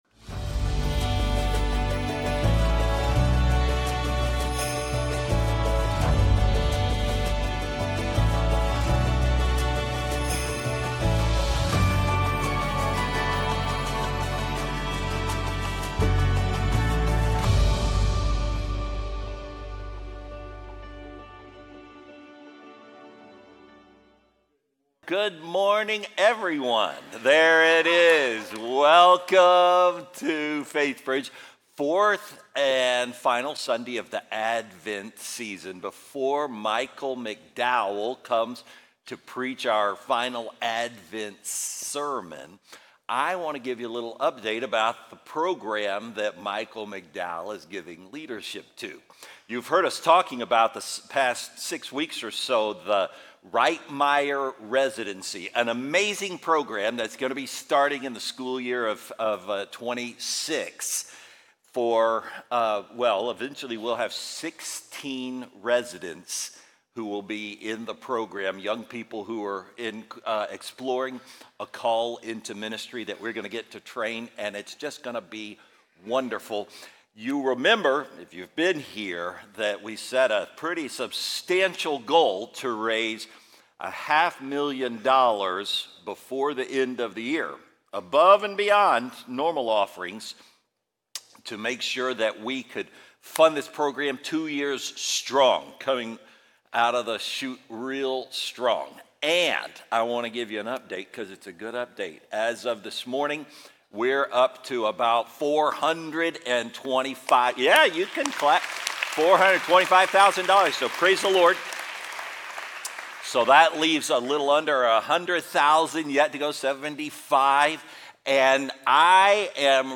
Faithbridge Sermons Glad Tidings for the Ordinary Dec 21 2025 | 00:39:08 1x 00:00 / 00:39:08 Subscribe Share Apple Podcasts Spotify Overcast RSS Feed Share Link Embed